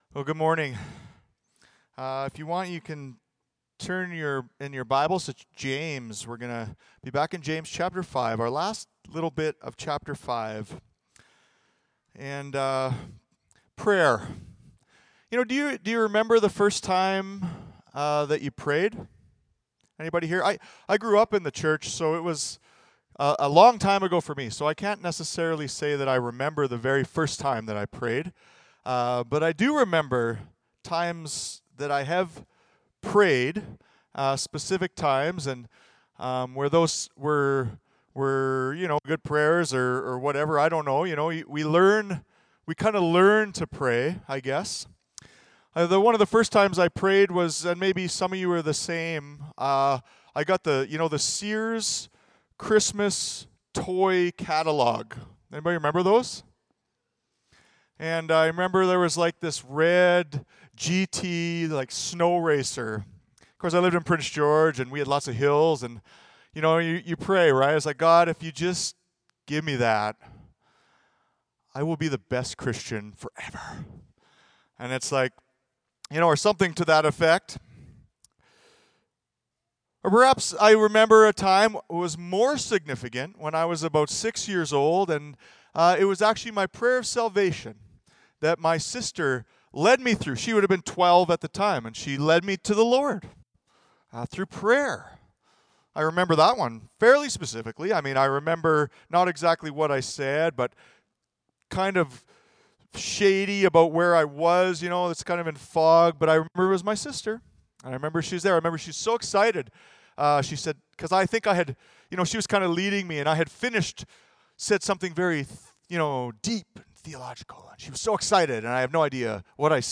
Audio Sermons - Clive Baptist Church